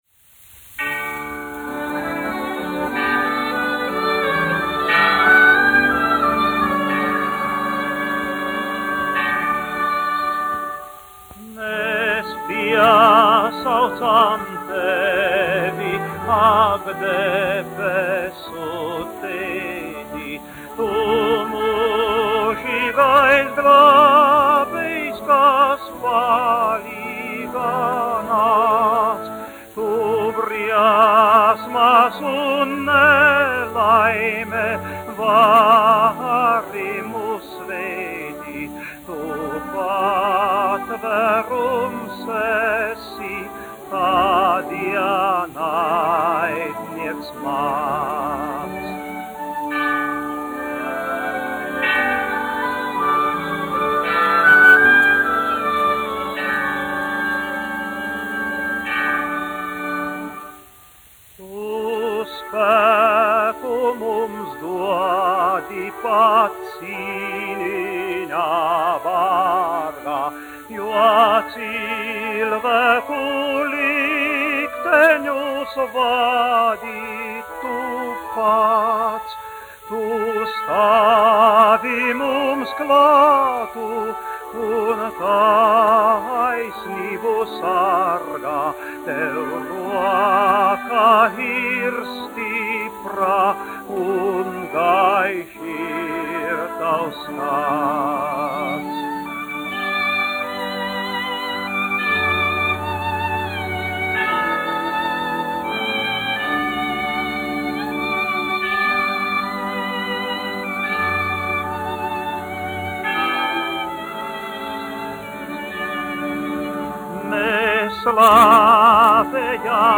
1 skpl. : analogs, 78 apgr/min, mono ; 25 cm
Dziesmas (augsta balss) ar orķestri
Latvijas vēsturiskie šellaka skaņuplašu ieraksti (Kolekcija)